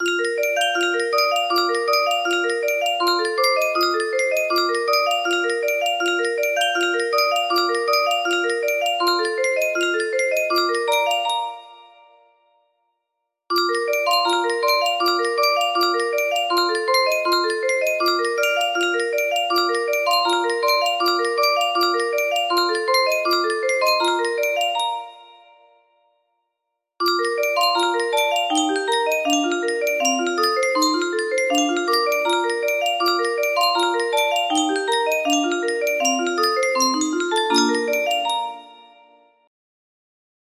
London Bridge, Mary Had a Little Lamb and Studio auf einer Reis' music box melody
Full range 60